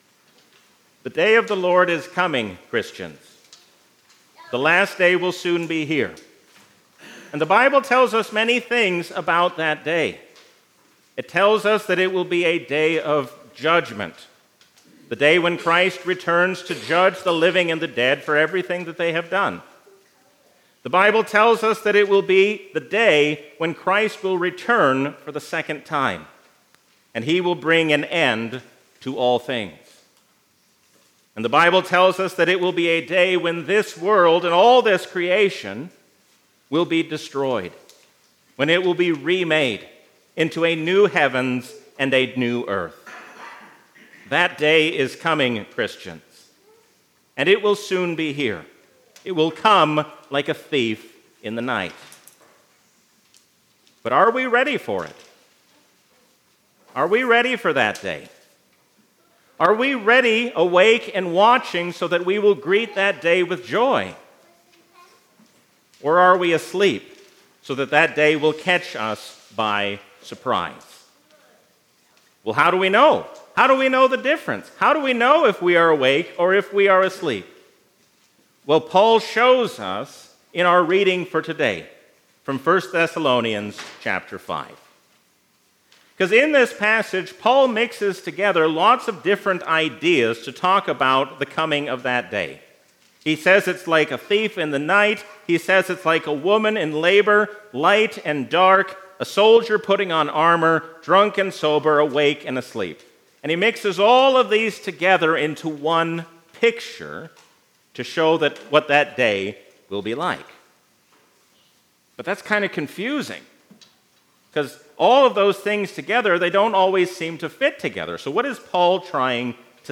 A sermon from the season "Trinity 2024." The New Jerusalem shows us what it will be like to be with God in glory forever.